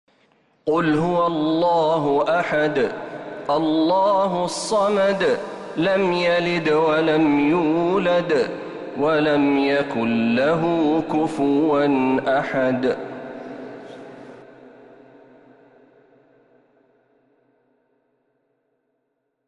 سورة الإخلاص كاملة من الحرم النبوي